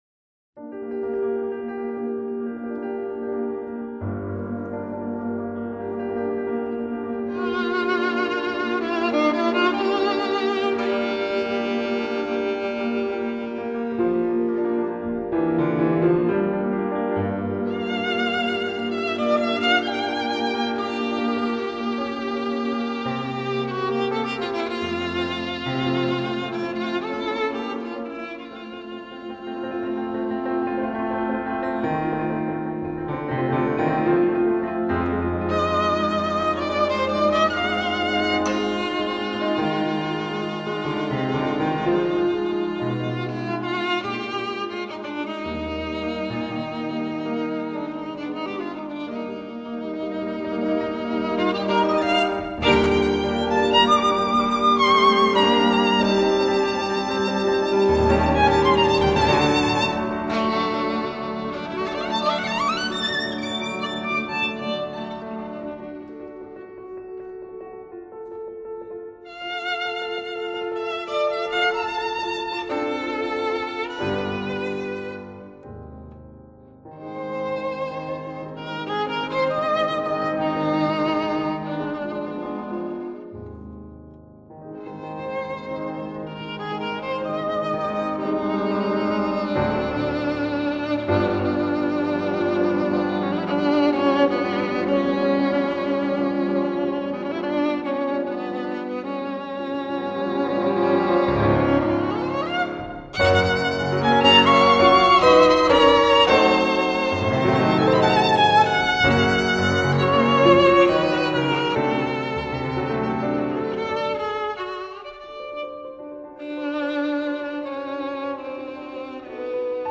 曲调优美，演奏细腻，丝丝入扣
爆发力强，有着惊人的速度和技巧